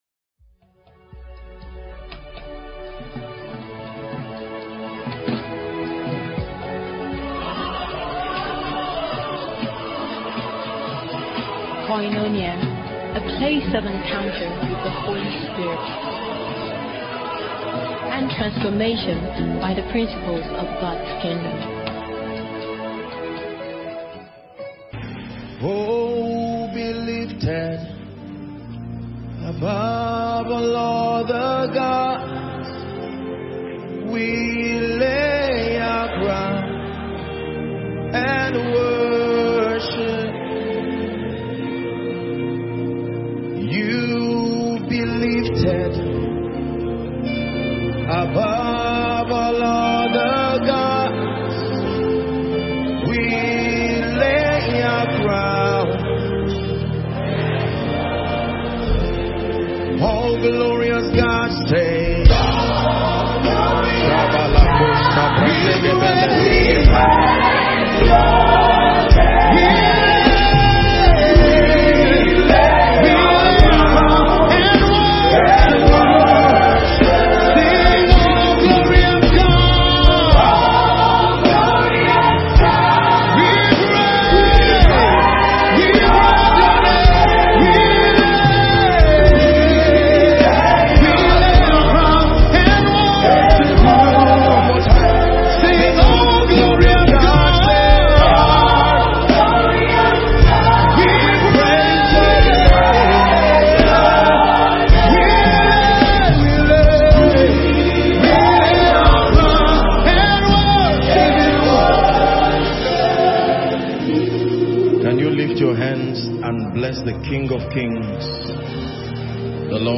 (Koinonia Abuja 2023)
In this sermon